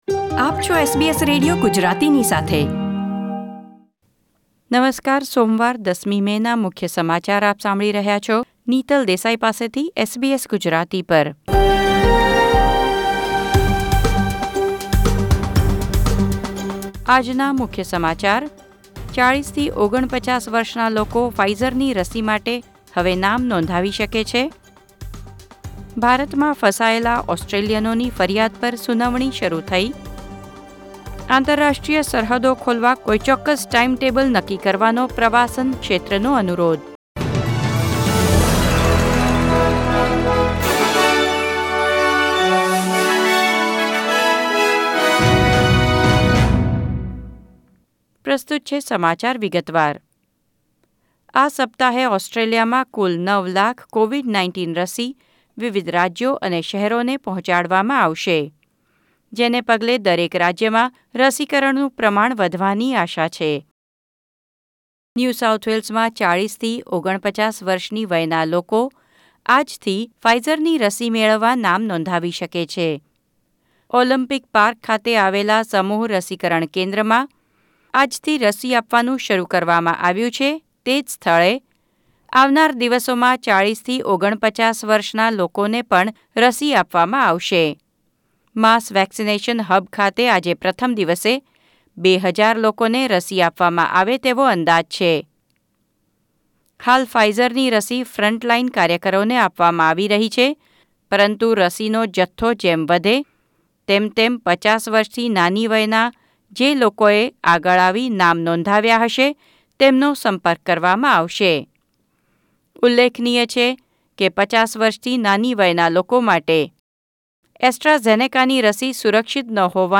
SBS Gujarati News Bulletin 10 May 2021